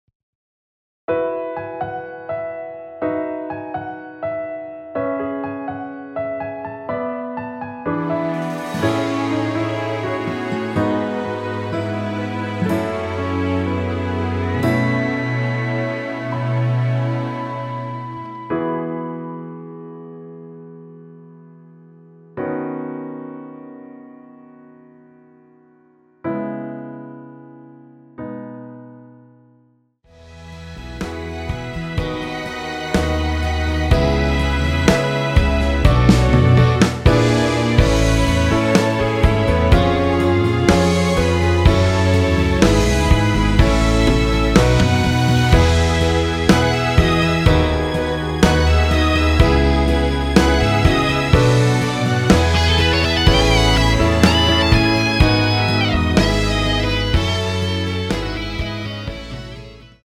원키에서(+3)올린 MR입니다.
앞부분30초, 뒷부분30초씩 편집해서 올려 드리고 있습니다.
중간에 음이 끈어지고 다시 나오는 이유는